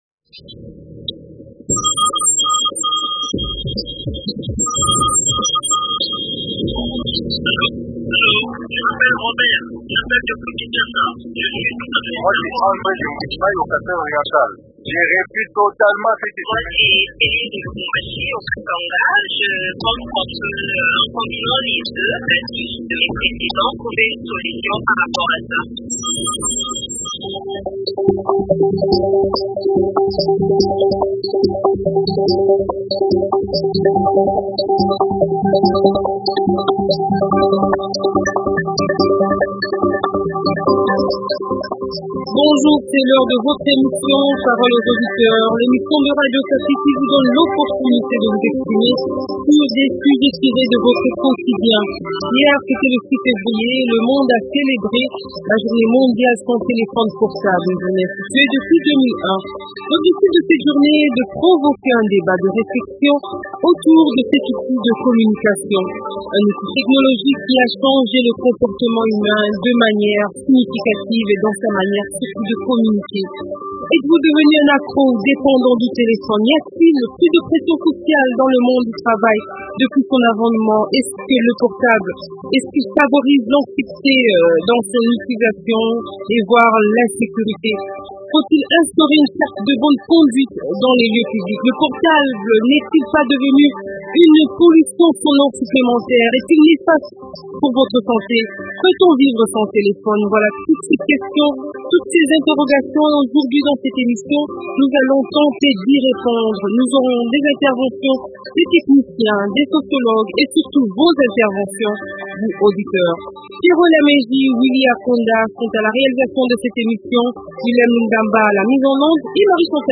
Au cours de cette émission, nous avons tenté de répondre à toutes ces interrogations.